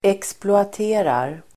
Uttal: [eksploat'e:rar]